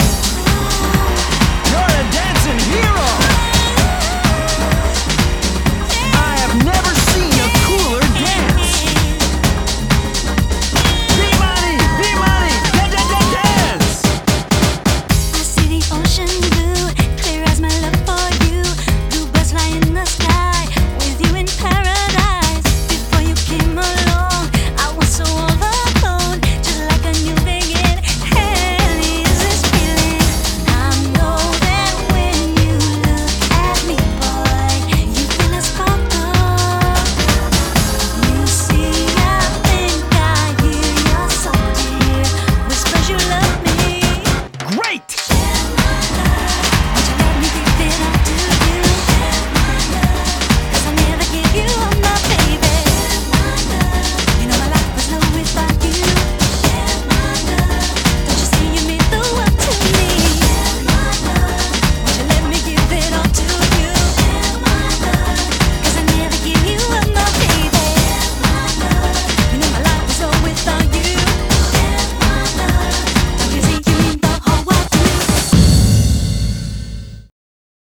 BPM127
Audio QualityPerfect (High Quality)
This is a groovier take on the song.